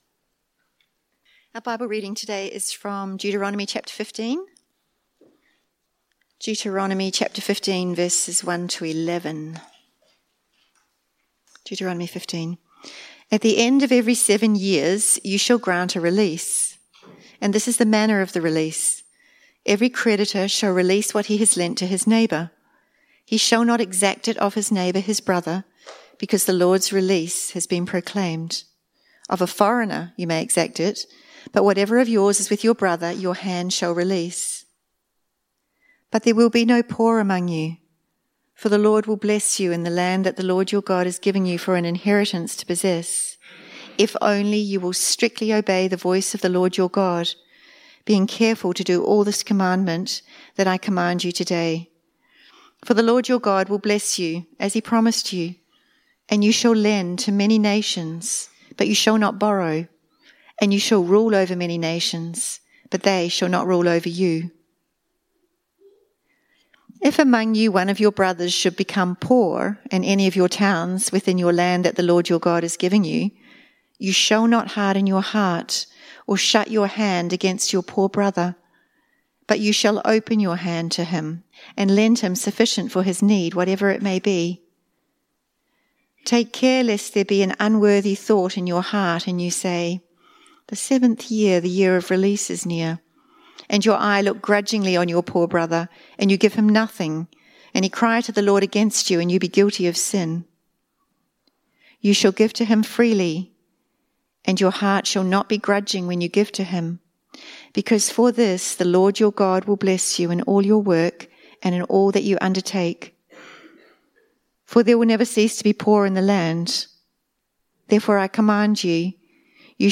Sermons from the South Barwon Christian Reformed Church in Geelong, Victoria, Australia.